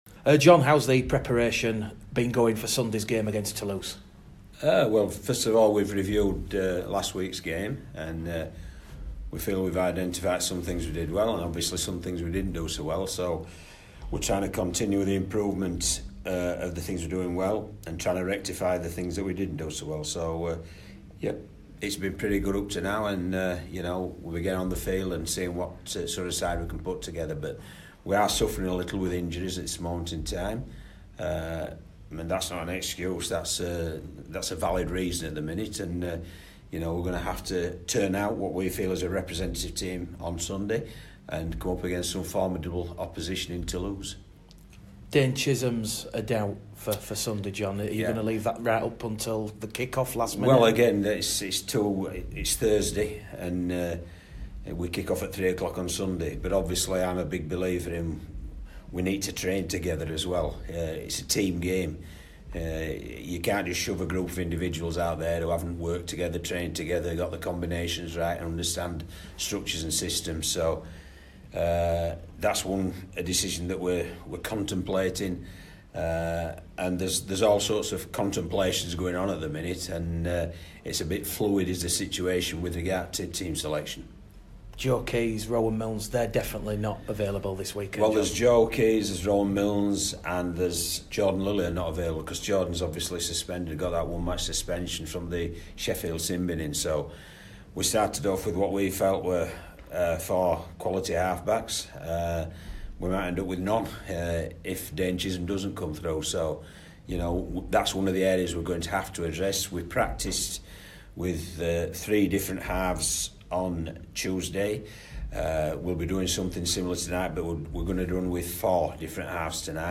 Pre-Toulouse Interview